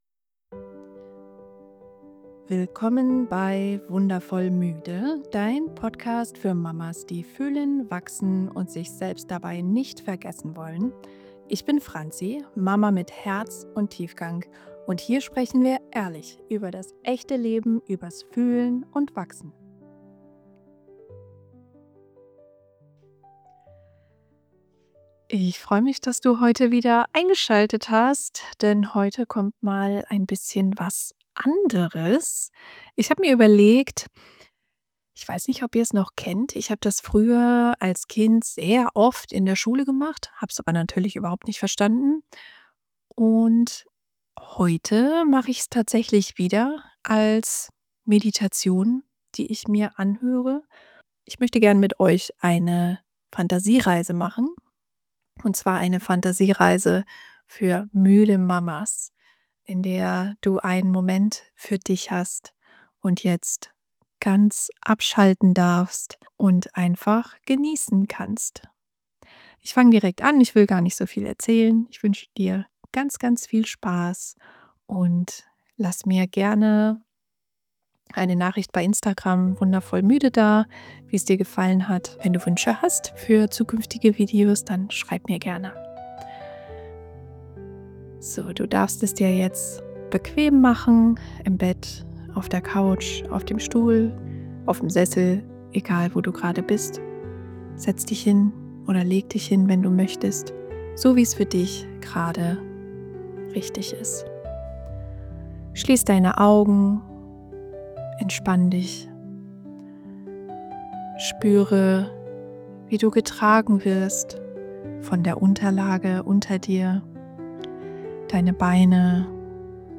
In dieser Episode nehme ich dich mit auf eine sanfte Fantasiereise: speziell für erschöpfte Mamas geschrieben beruhigend gesprochen & mit liebevoller Musik unterlegt zum Auftanken, Innehalten und dich selbst wieder spüren Drück jetzt auf Play – und gönn dir, was du sonst immer nur gibst: echte Fürsorge.
Fantasiereise_fertig.mp3